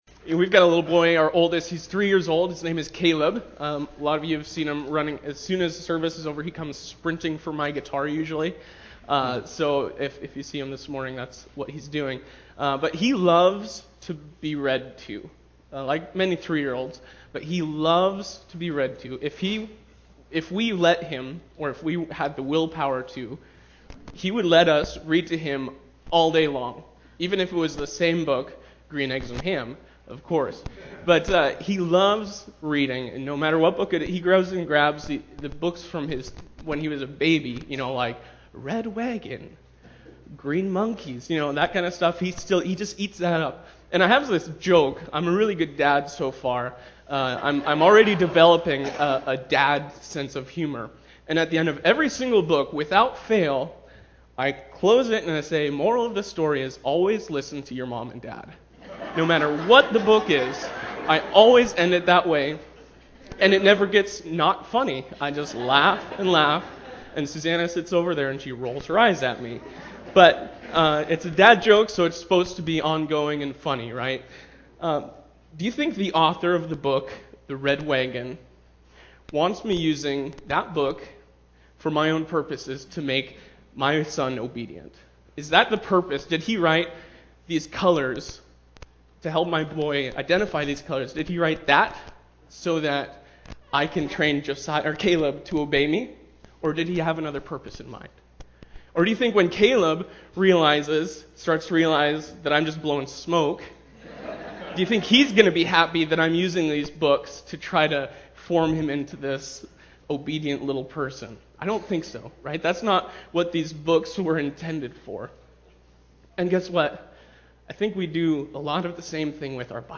Guest Sermons